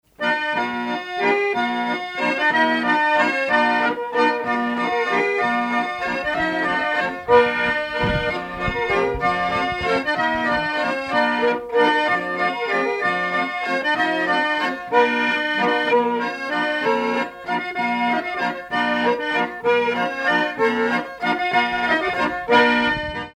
Saint-Nicolas-la-Chapelle
Pièce musicale inédite